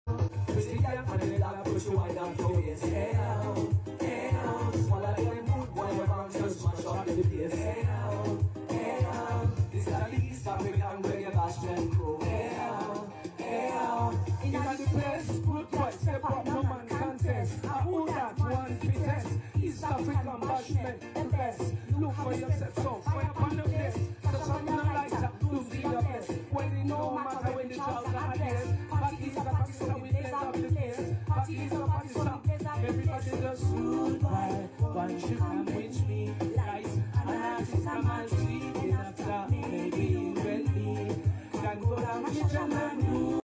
Performing ,Live